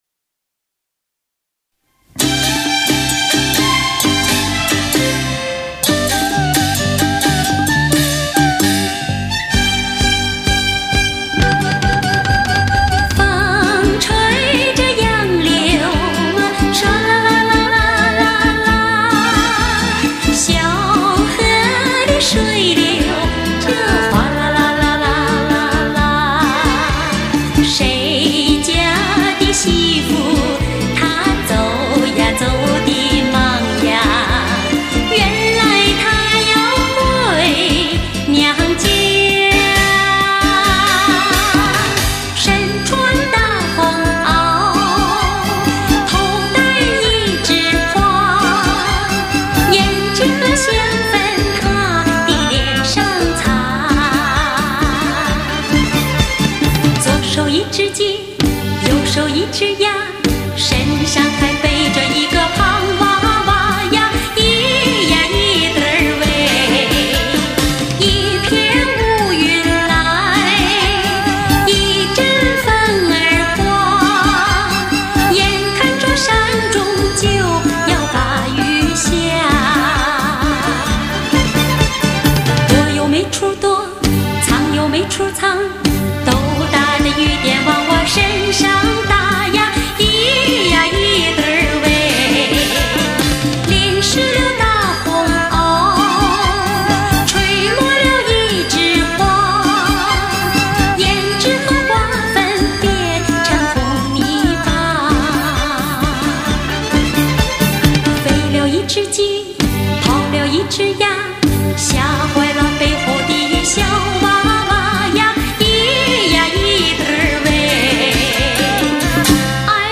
东方璀璨的明珠，倾倒你的舞台现场，最具时代意义的声音，最淳朴的东方情结！
本系列DTS6.1CD全部采用德国真空管录音，通过最先进的多声道现场录音技术处理，力求还原最真实的舞台现场效果。